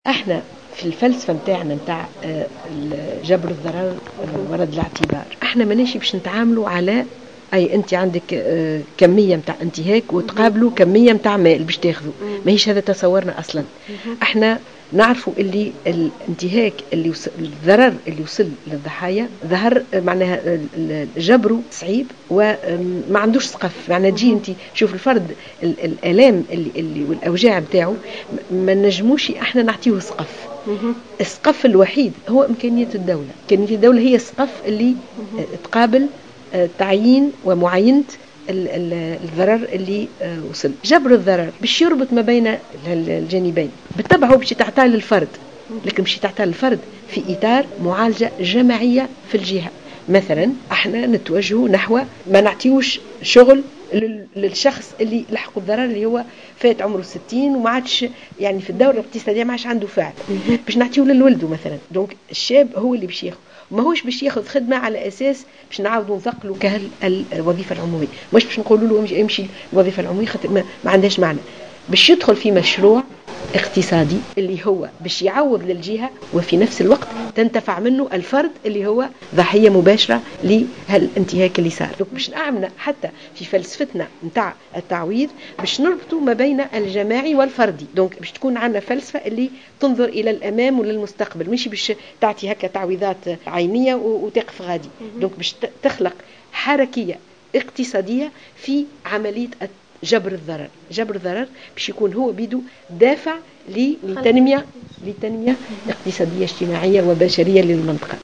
خلال ندوة دولية